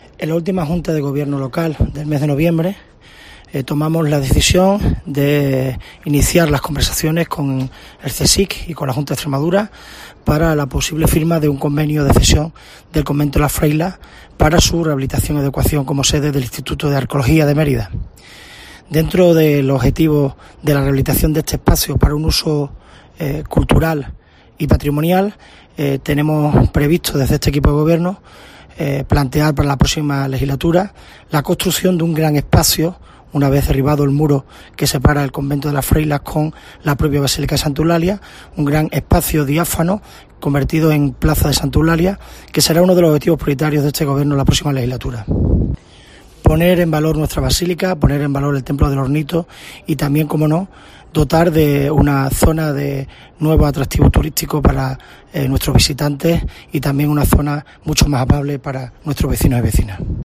Antonio R. Osuna anuncia la creación de una plaza en honor a Santa Eulalia